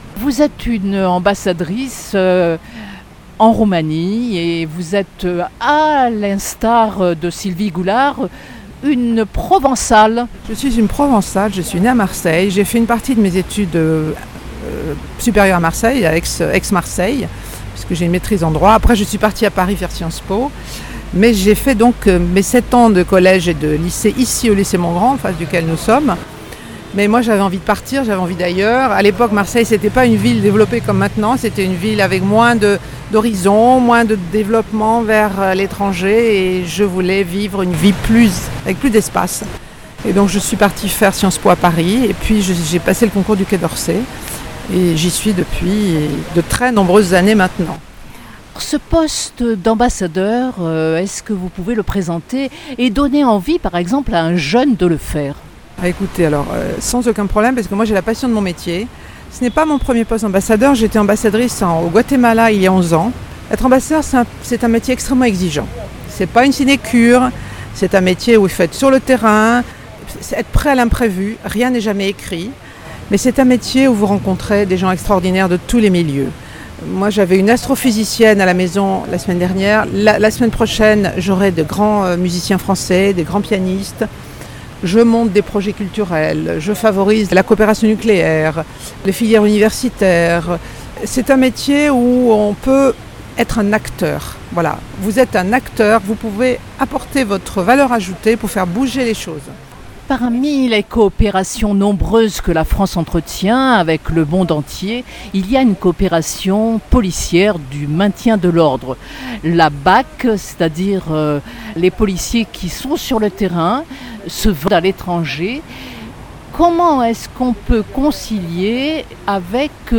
son_copie_petit-382.jpgEntretien avec Michèle Ramis, ambassadrice de France en Roumanie
michele_ramis_ambassadrice_de_f_en_roumanie_30_08_2019.mp3